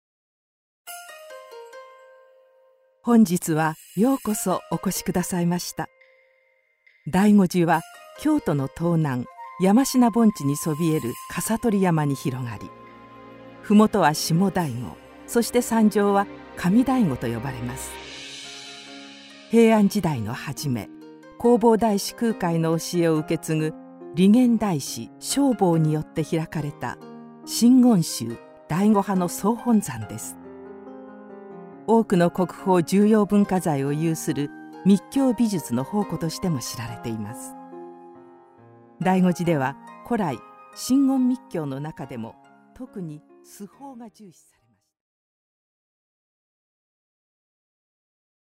仏像大使のみうらじゅんさん＆いとうせいこうさんの特別解説付き。
「京都・醍醐寺」展音声ガイドサンプル